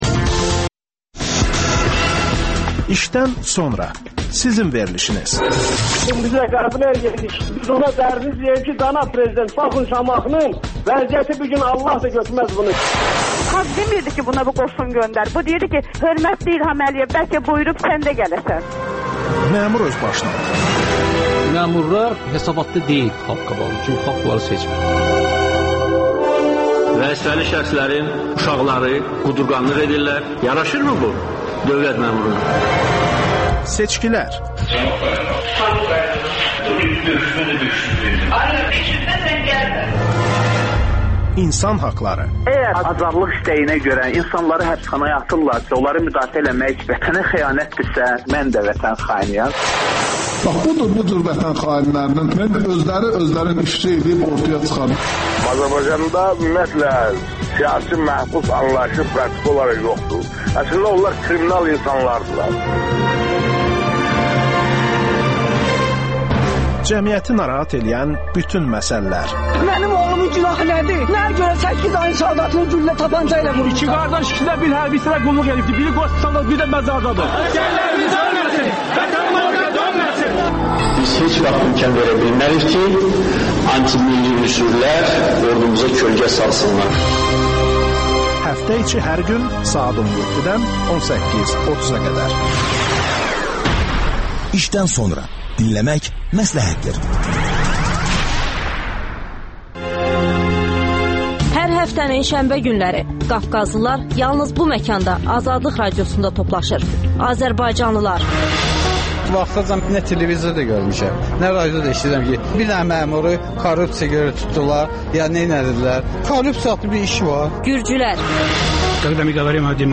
AzadlıqRadiosunun müxbirləri canlı efirdə ölkədən və dünyadan hazırlanmış xəbərləri diqqətə yetirirlər.